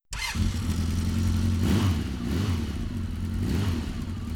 motorBikeEngineStart.wav